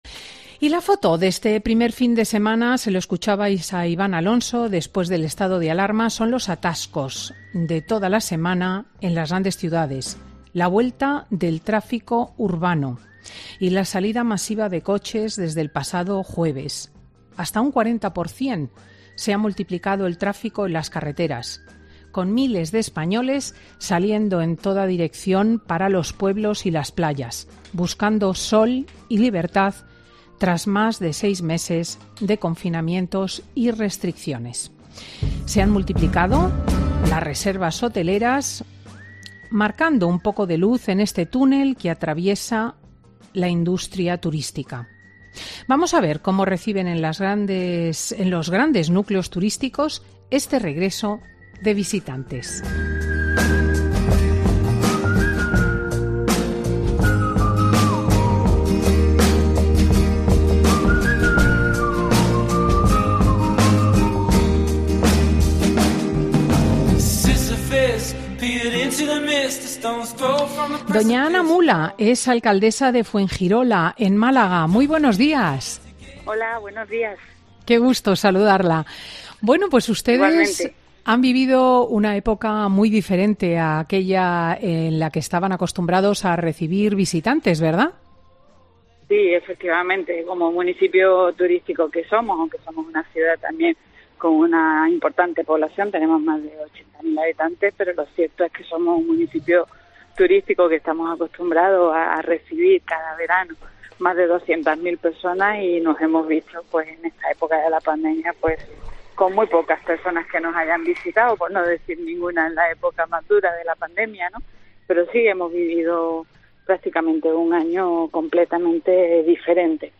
La alcaldesa de Fuengirola y el alcalde de Benidorm han contado en 'Fin de Semana' cómo van a recibir a las pesonas que vayan a sus ciudades a...